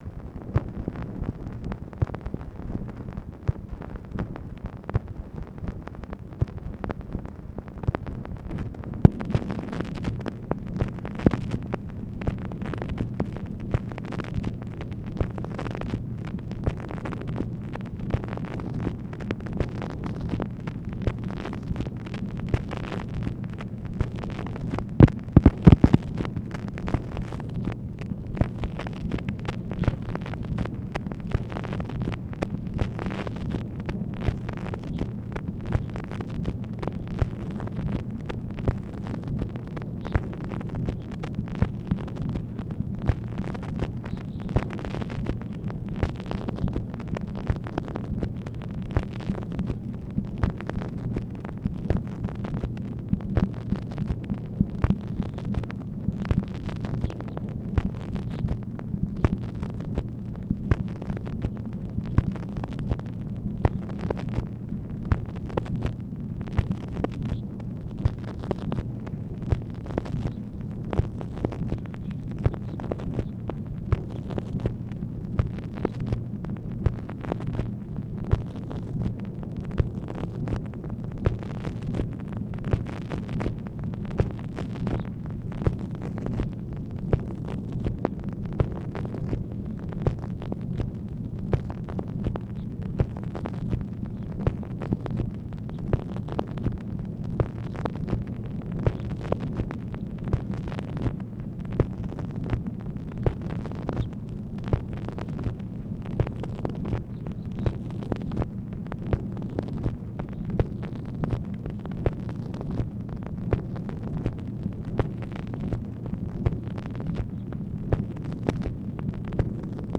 Conversation with (possibly) ABE FORTAS and (possibly) BILL MOYERS, August 29, 1966
Secret White House Tapes | Lyndon B. Johnson Presidency